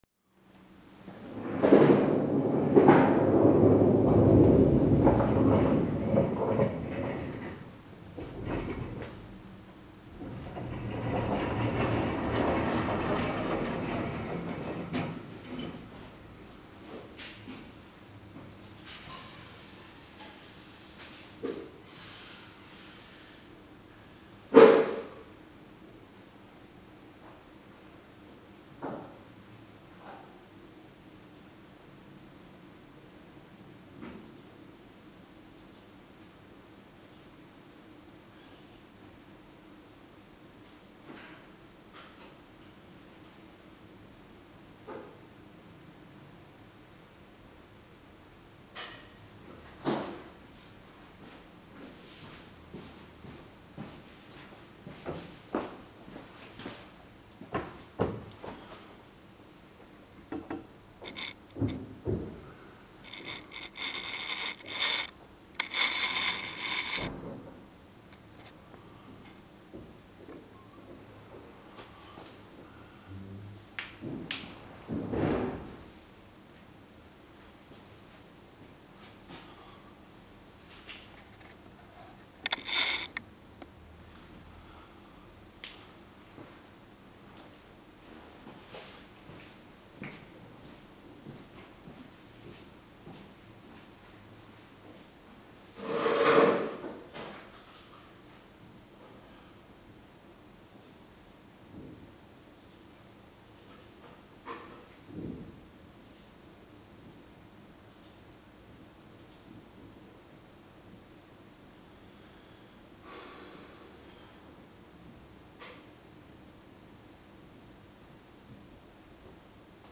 2021-02-08 Schnee durchs Fenster (Nikon D90) (Original Video Ton).flac
(Fieldrecording Video)
Aufgenommen durch abspielen der original Video Dateien mit VLC Player und Aufnehmen mit Audacity.